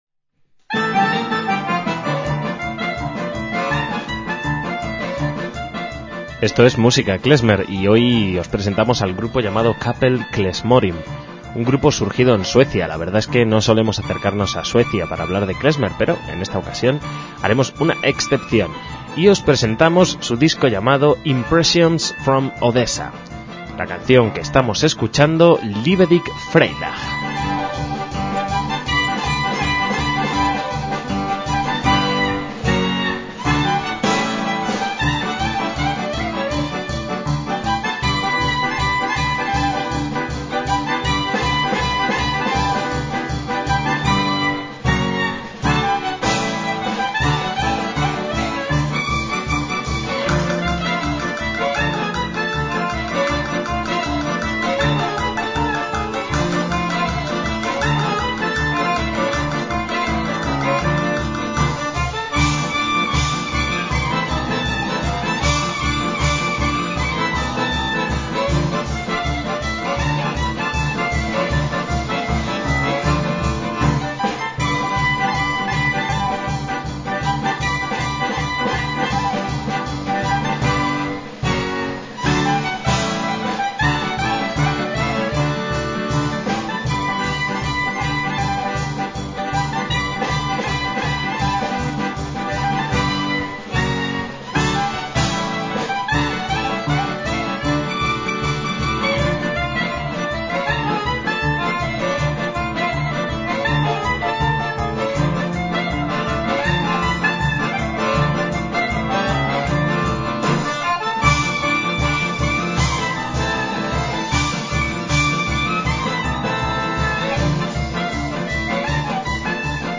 MÚSICA KLEZMER
violín
clarinete
acordeones
percusión